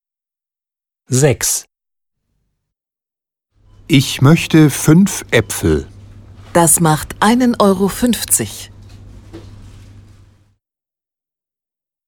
Dialog 6: